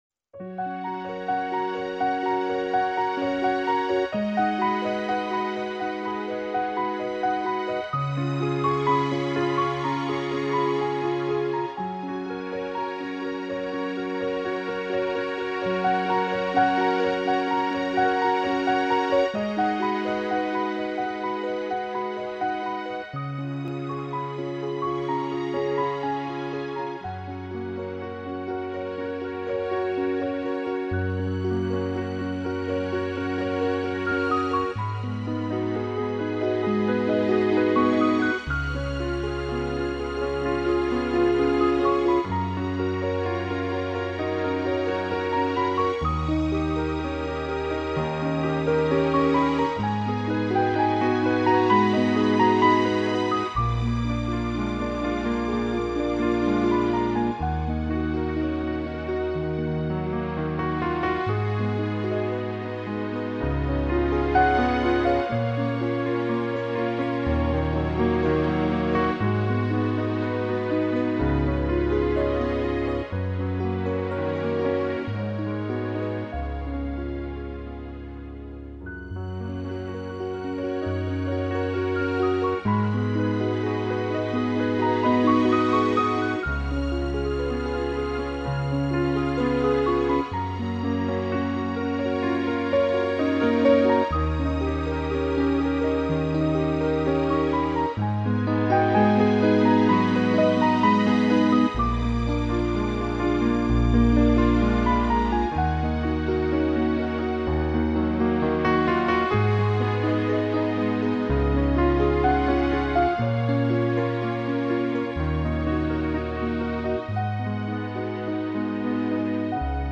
慢慢聽吧，相信大夥會跟我一樣，愛上這樣的琴，這樣用情感去敲擊而出的抒情鋼琴。